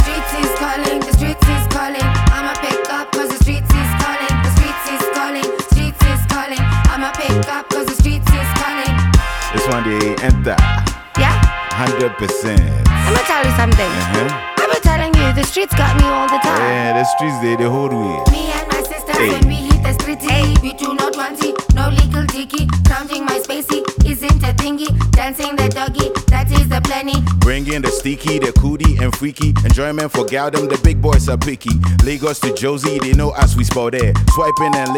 Рингтоны
# Jazz